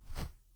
gassy-footstep1.wav